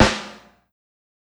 SNARE_LIGHT.wav